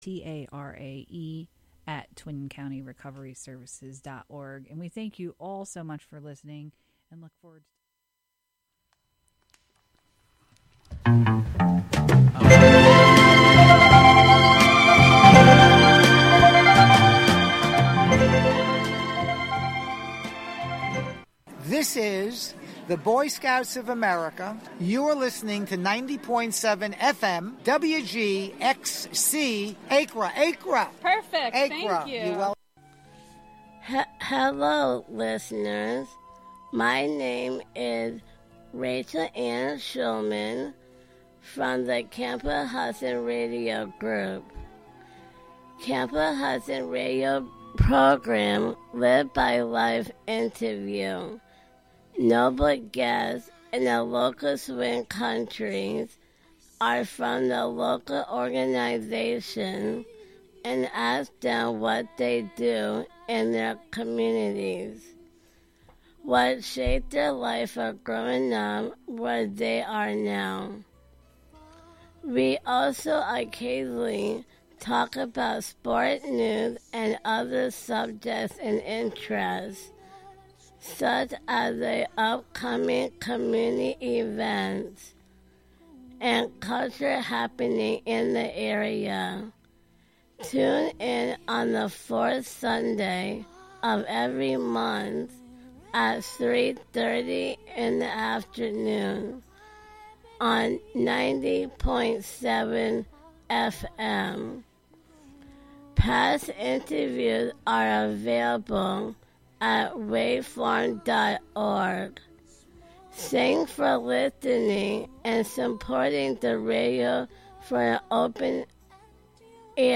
for fun and quirky interviews with community leaders and local personalities